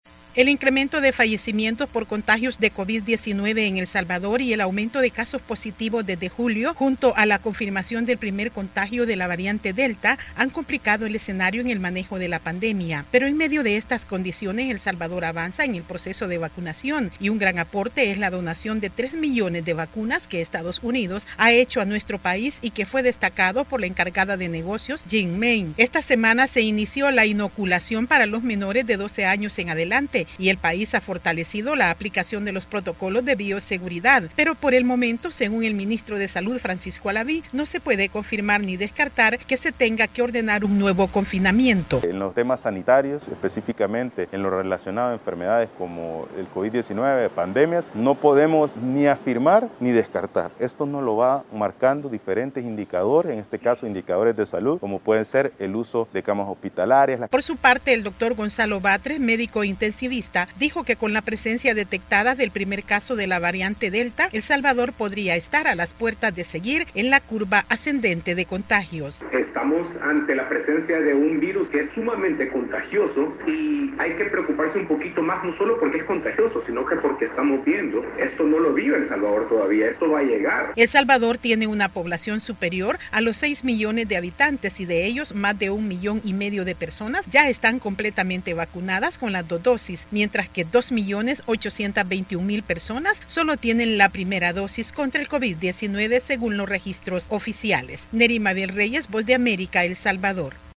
Pese al avance en la vacunación El Salvador registra un aumento en los contagios y fallecimientos debido al COVID-19. Desde San Salvador informa